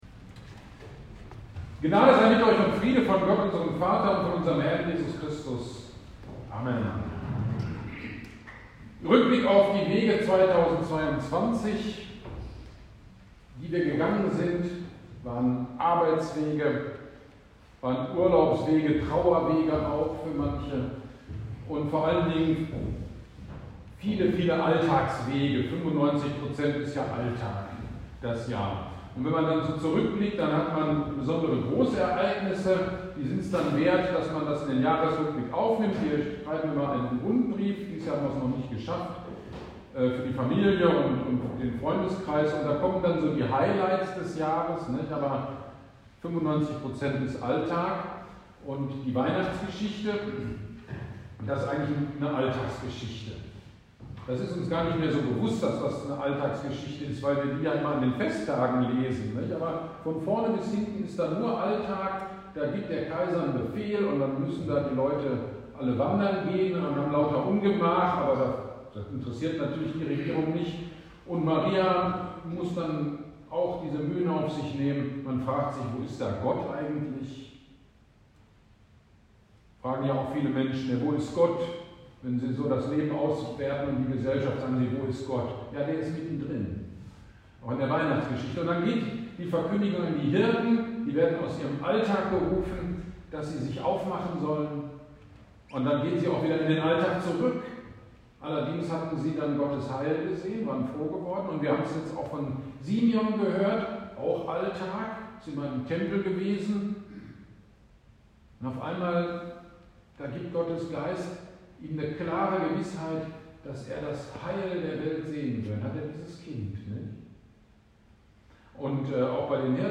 Gottesdienst zum Jahreswechsel 22/23 – Predigt über Psalm 16,11
Gottesdienst-zum-Jahreswechsel-Predigt-ueber-Psalm-16.mp3